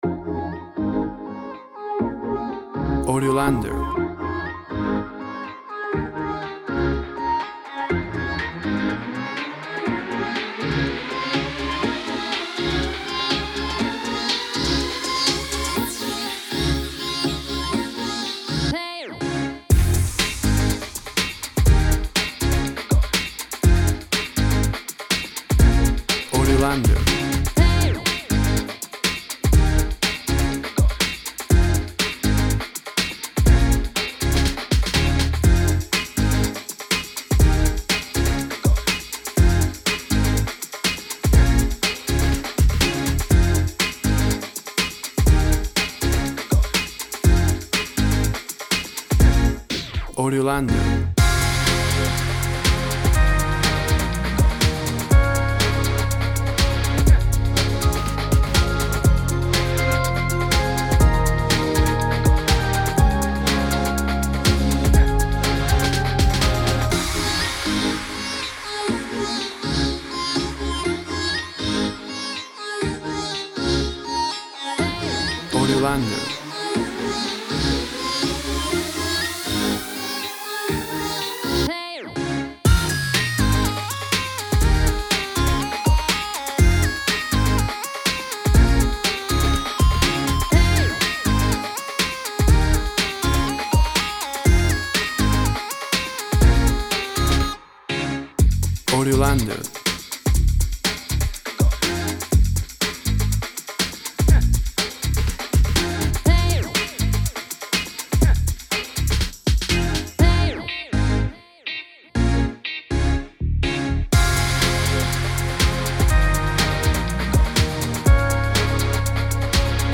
WAV Sample Rate 24-Bit Stereo, 44.1 kHz
Tempo (BPM) 121